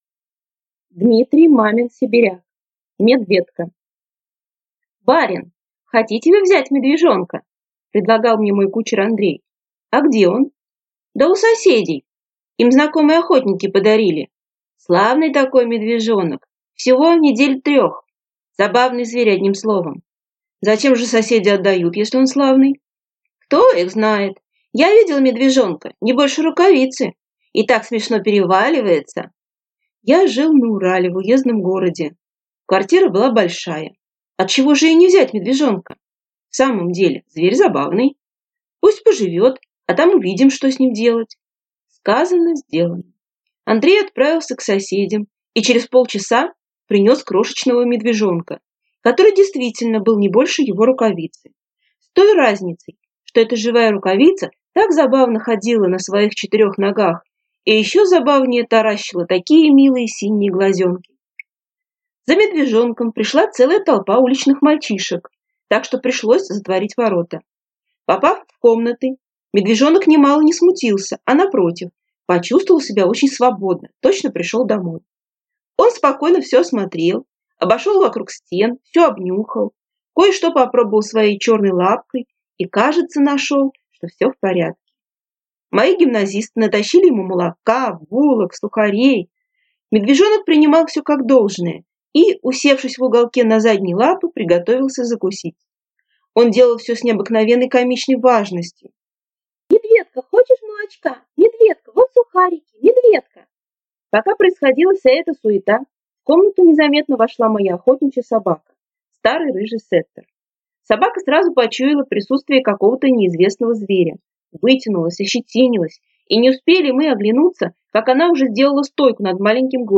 Аудиокнига Медведко | Библиотека аудиокниг
Aудиокнига Медведко Автор Дмитрий Мамин-Сибиряк Читает аудиокнигу учитель.